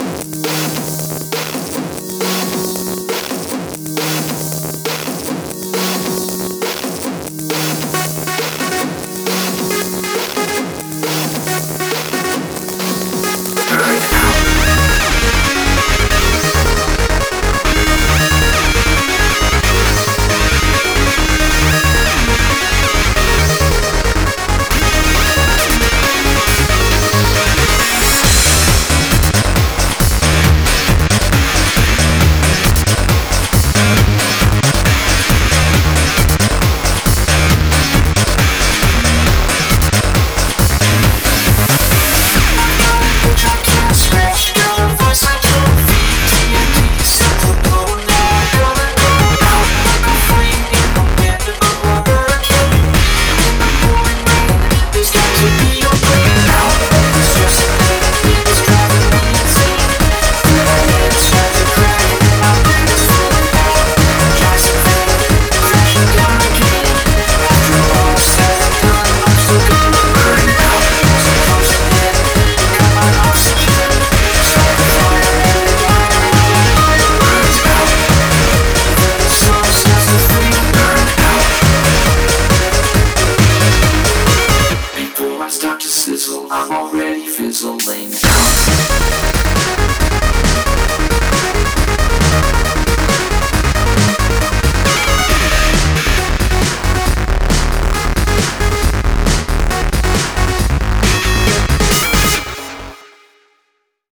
BPM136
Audio QualityPerfect (High Quality)